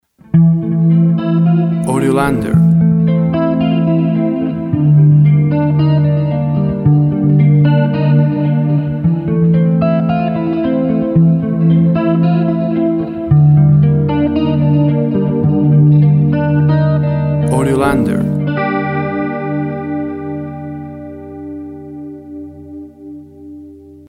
A mysterious atmosphere, created with an electric guitar.
Tempo (BPM) 55